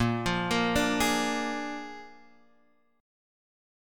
A#7 chord